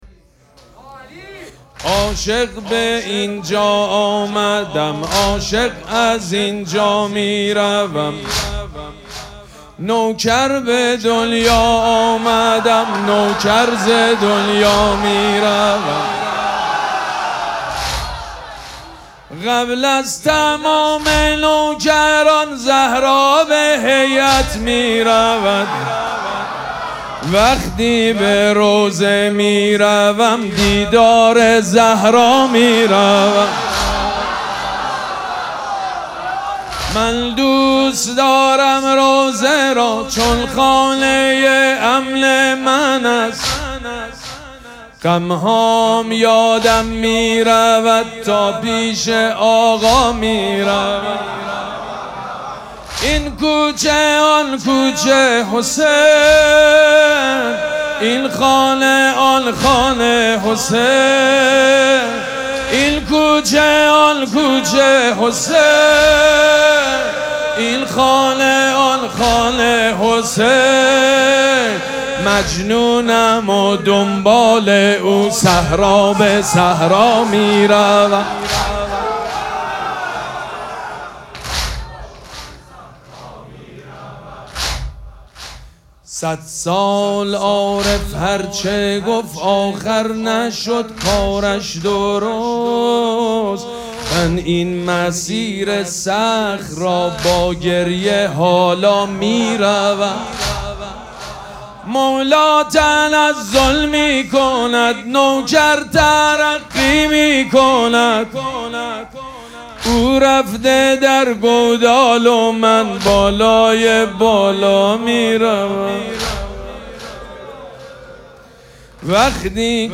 شب چهارم مراسم عزاداری دهه دوم فاطمیه ۱۴۴۶
مداح
حاج سید مجید بنی فاطمه